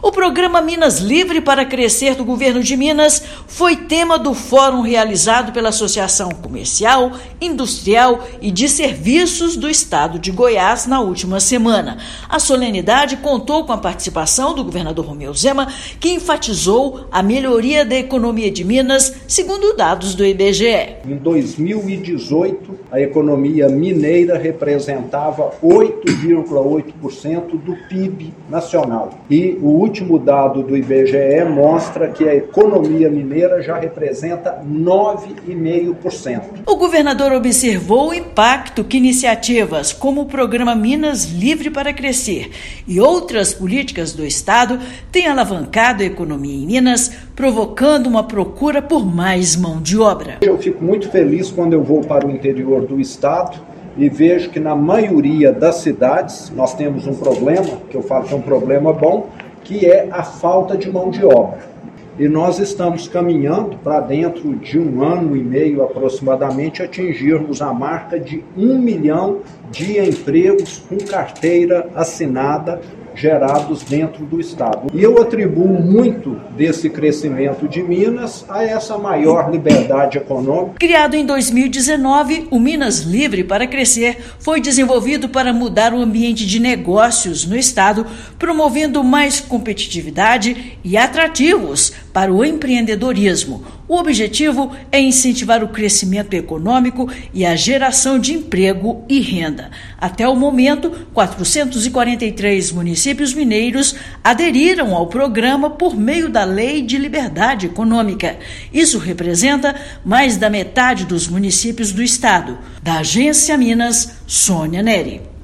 Projeto mineiro de liberdade econômica para os municípios foi apresentado pelo governador Romeu Zema e pelo secretário Fernando Passalio. Ouça matéria de rádio.